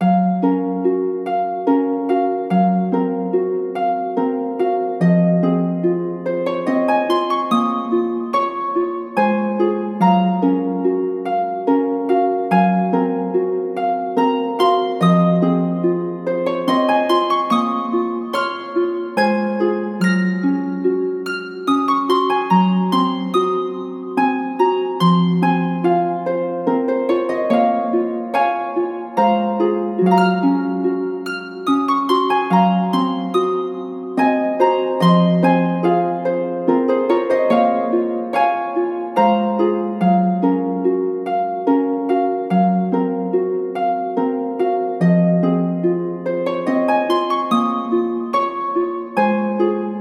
しっとりとした落ち着いたハープの曲です